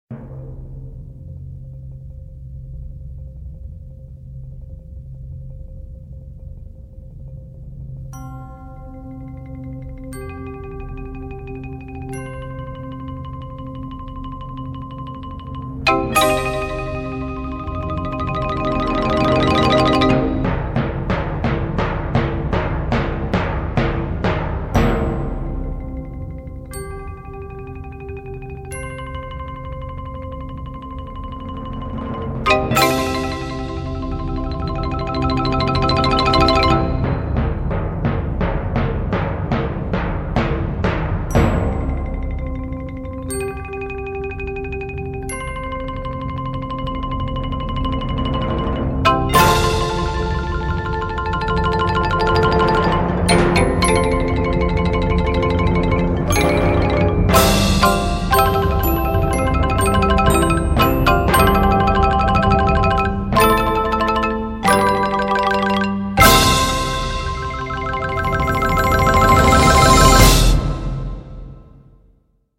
Voicing: Percussion Choir